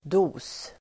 Uttal: [do:s]